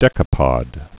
(dĕkə-pŏd)